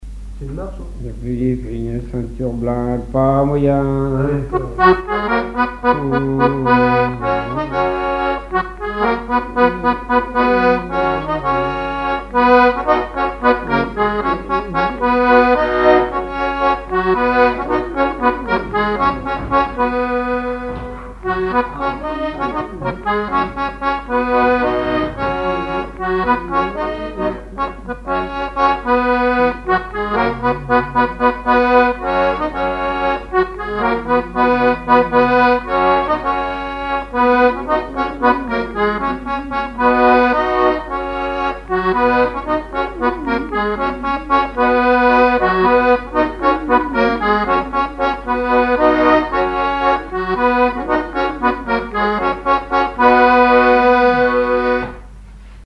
Fonction d'après l'analyste gestuel : à marcher
Genre énumérative
accordéon diatonique
Pièce musicale inédite